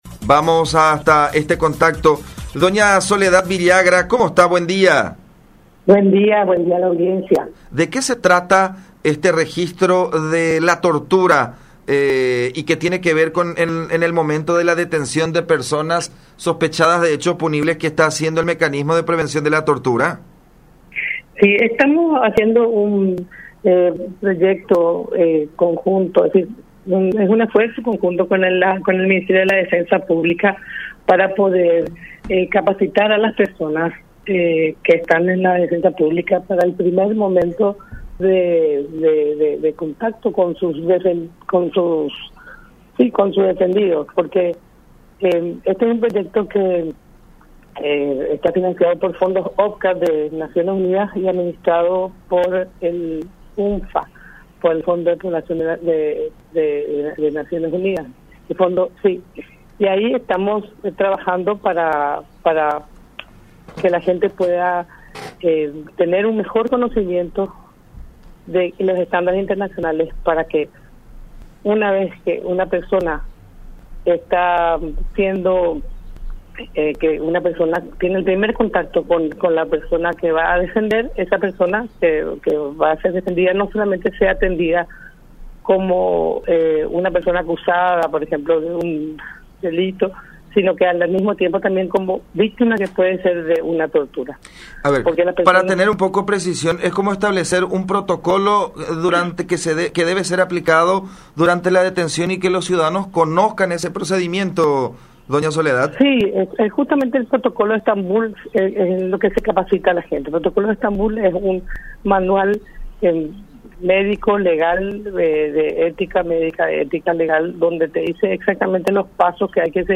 “Estamos trabajando para que la gente tenga un mejor conocimiento de los estándares internacionales. Una vez que el funcionario de Defensa Pública entra en un primer contacto con la persona que va a defender, se busca que esta no sea solamente atendida como una persona acusada, sino también como víctima de una posible tortura”, explicó Soledad Villagra, comisionada del Mecanismo Nacional de Prevención de la Tortura, en diálogo con La Unión.